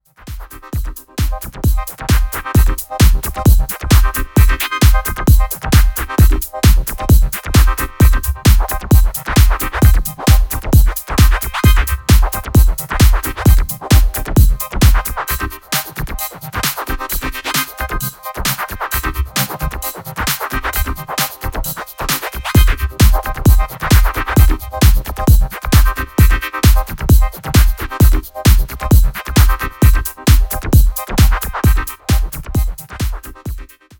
concise, effective club tracks, rooted in
a darker and more driving approach that stands out
with its controlled intensity.